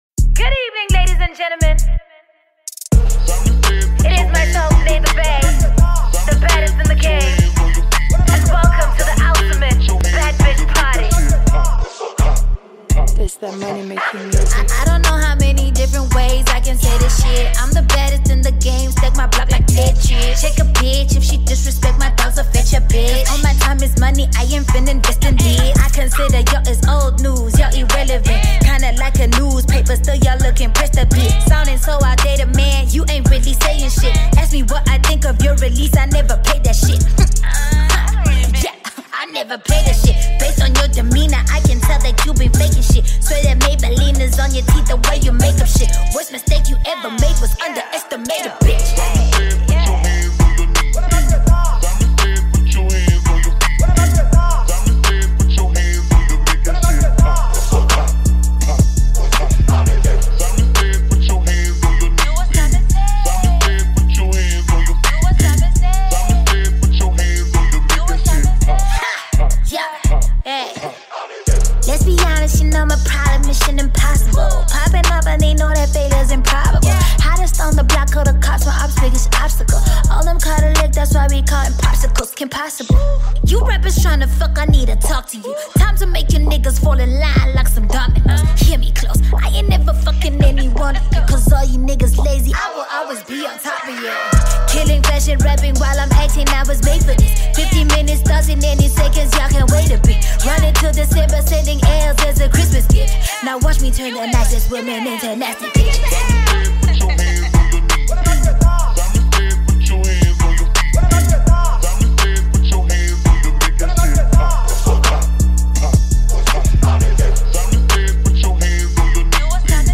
Talented South African hip pop vocalist and music dancer